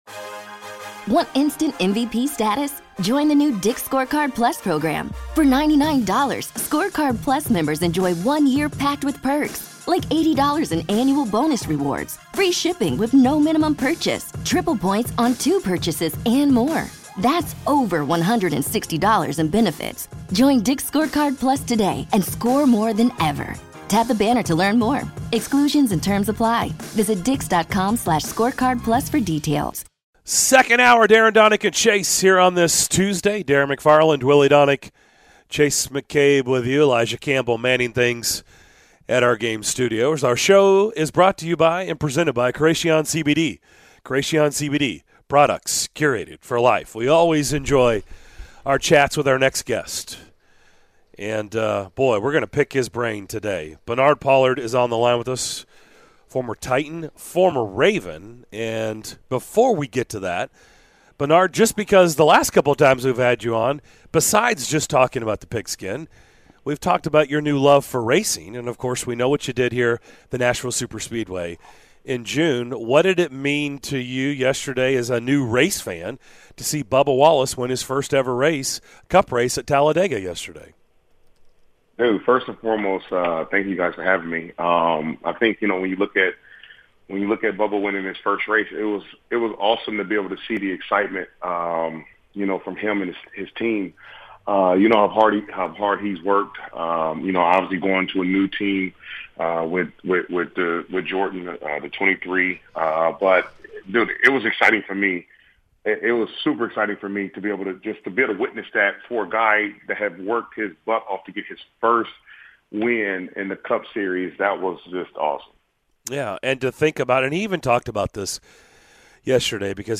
Former Titans safety Bernard Pollard joined the show, the guys try to fix the NFL overtime format and more during hour number 2 of today's show!